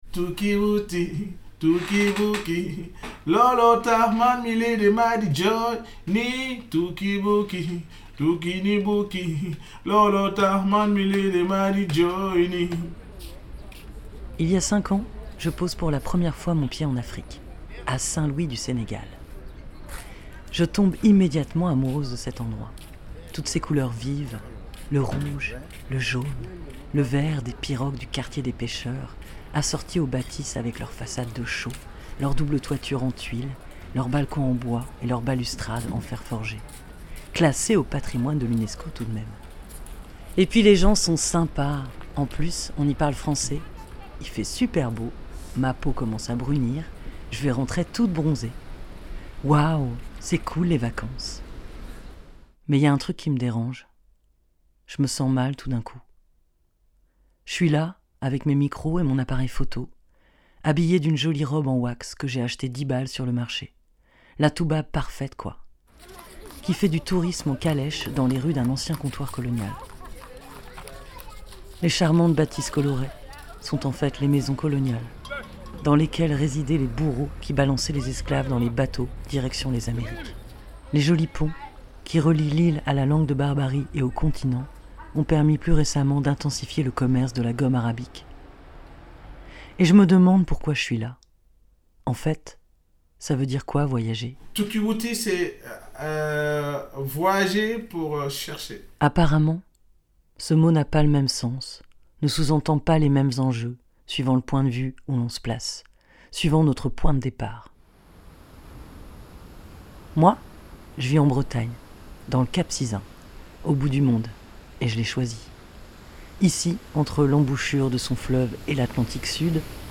Extraits sonores et vidéos en binaural